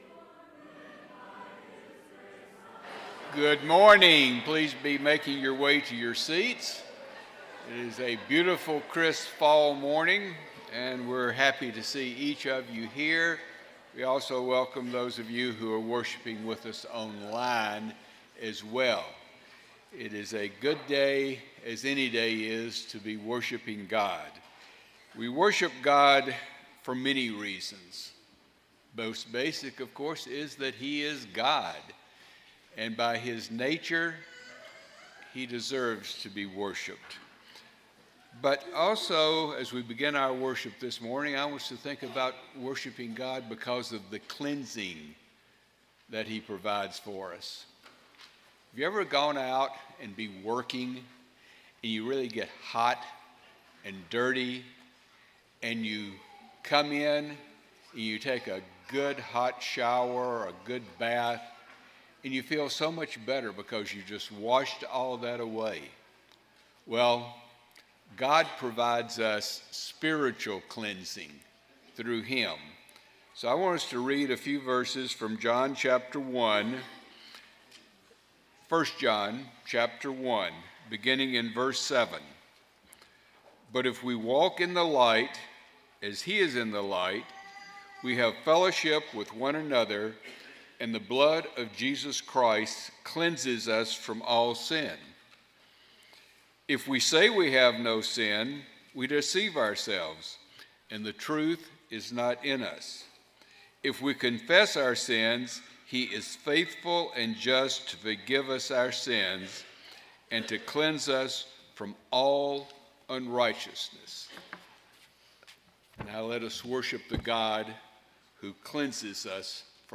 John 15:11, English Standard Version Series: Sunday AM Service